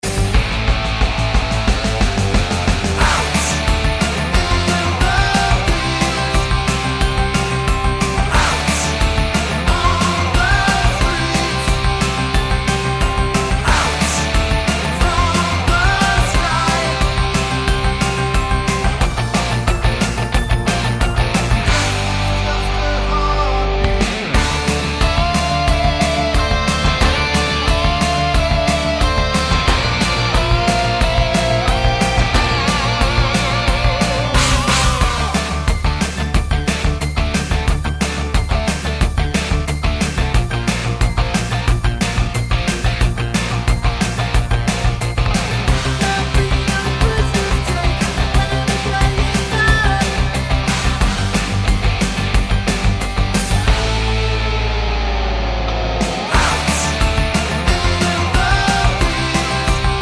Duet Version Rock 4:14 Buy £1.50